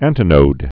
(ăntĭ-nōd)